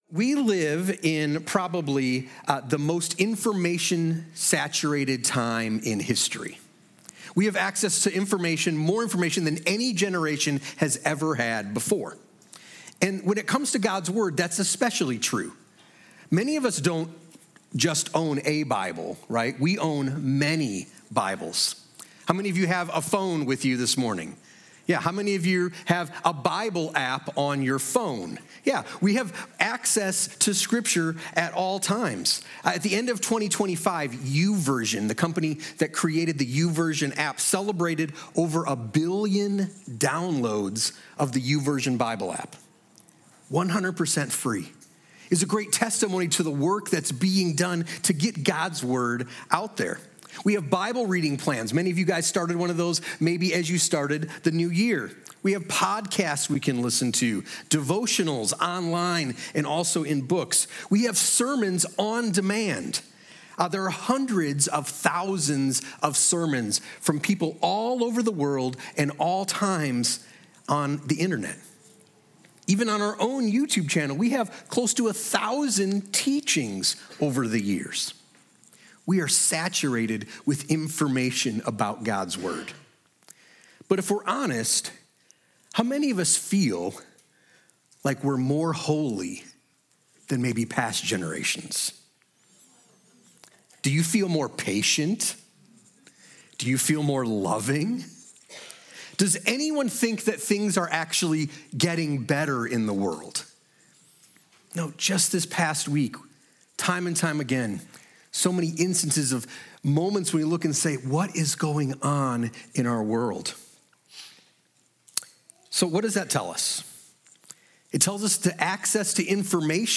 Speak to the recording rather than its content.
Meditating on God’s Word | Sermon at Seymour Christian Church | Romans 12